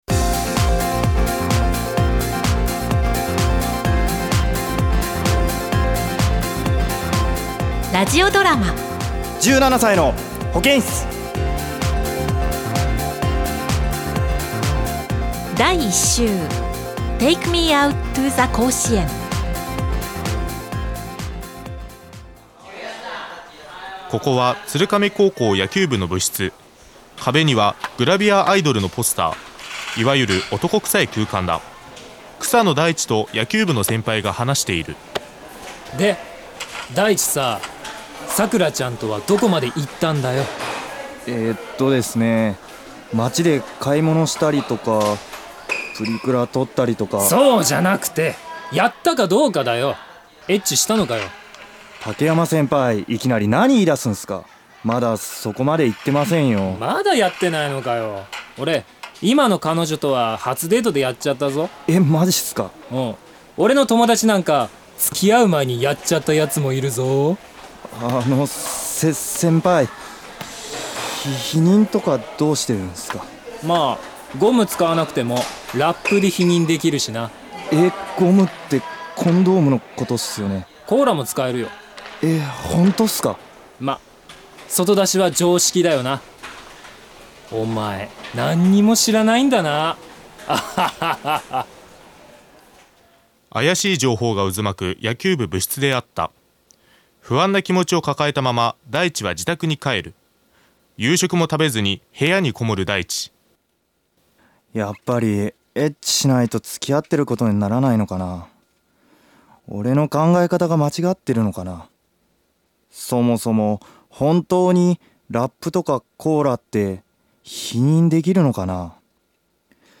ラジオドラマ17歳の保健室